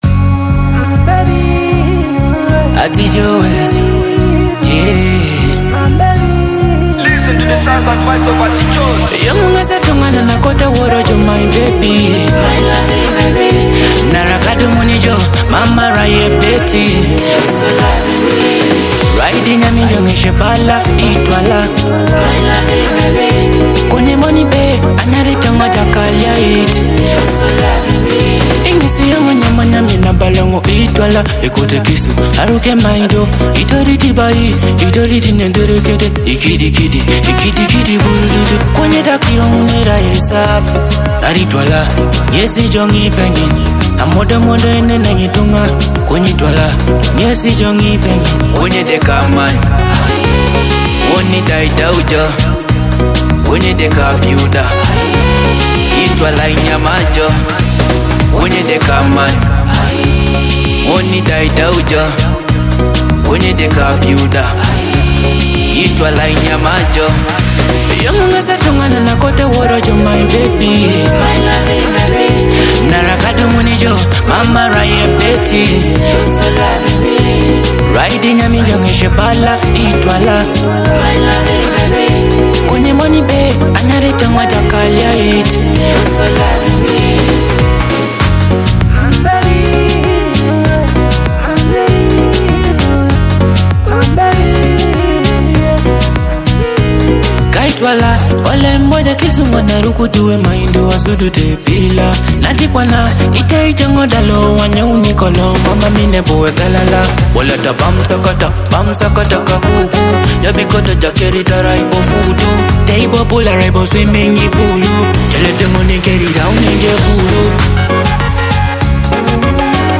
a fresh Teso dancehall love hit
captivating dancehall love song